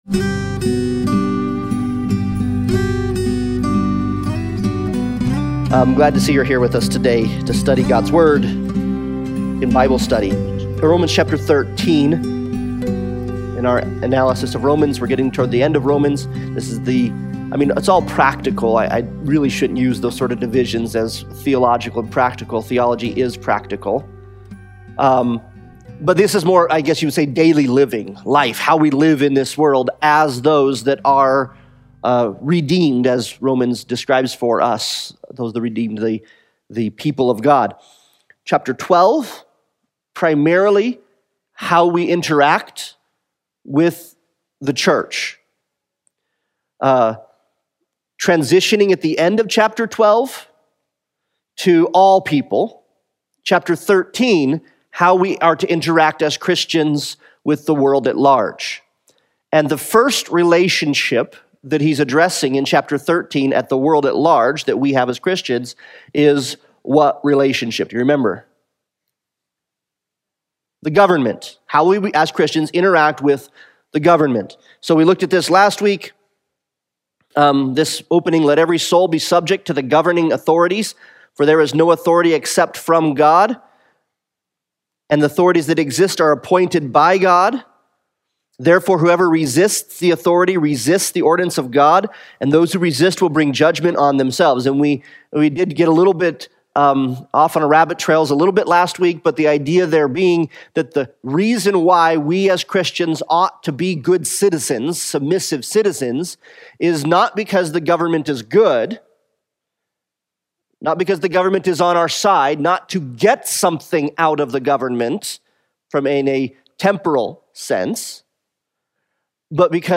Passage: Romans 13:2-7 Service Type: Sunday Bible Study « A Pretty Good Priest